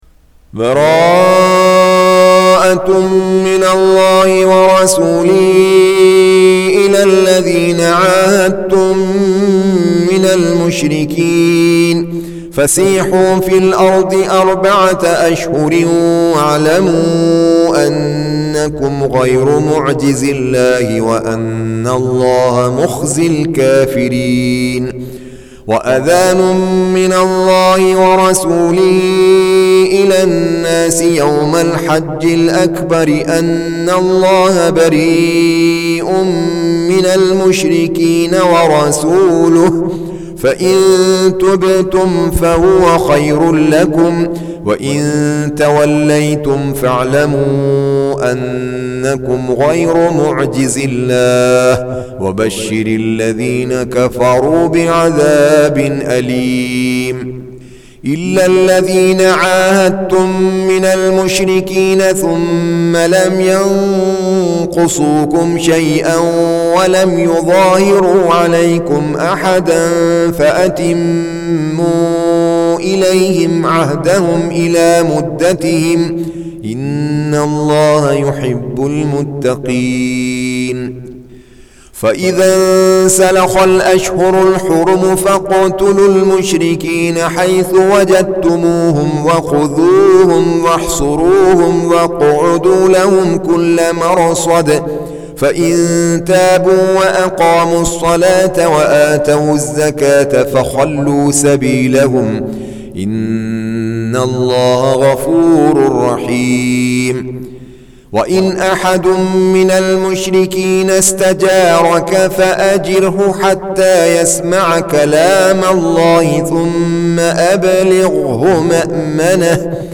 Surah Sequence تتابع السورة Download Surah حمّل السورة Reciting Murattalah Audio for 9. Surah At-Taubah سورة التوبة N.B *Surah Excludes Al-Basmalah Reciters Sequents تتابع التلاوات Reciters Repeats تكرار التلاوات